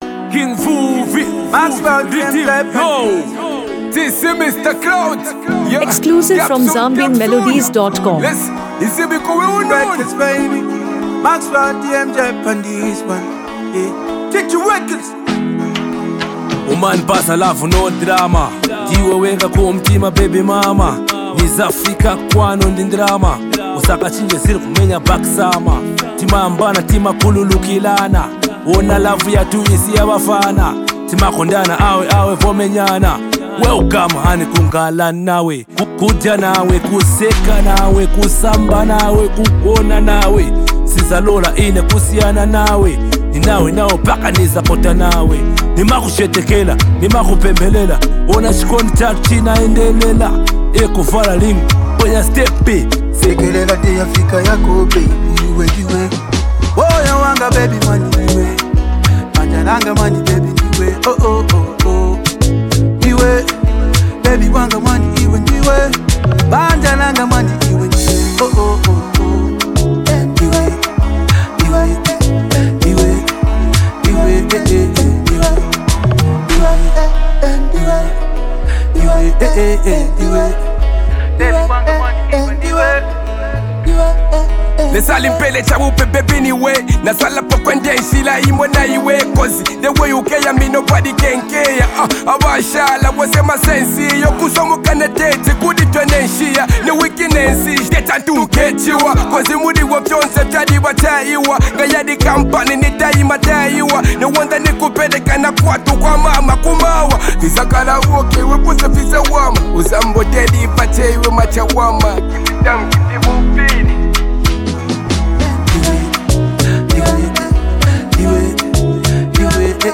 heartfelt Afro-love anthem
Latest Zambian Love Song
Genre: Afro-Beats/ RnB